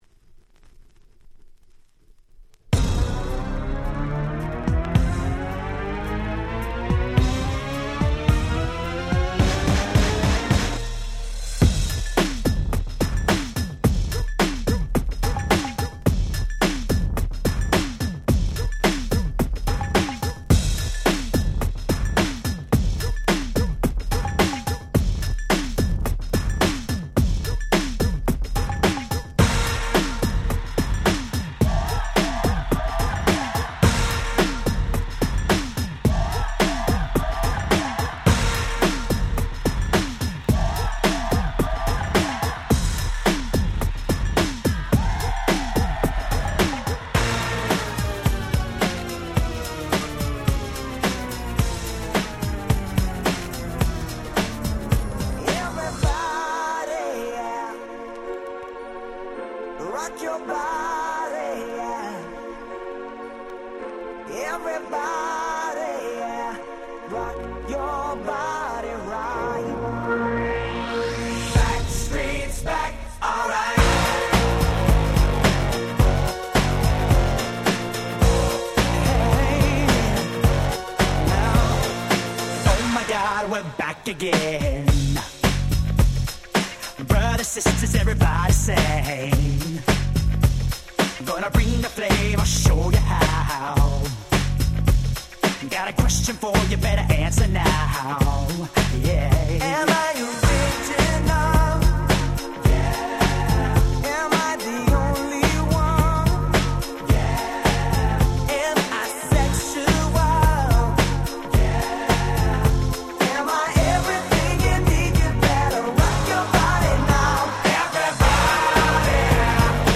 98' Super Hit Pops !!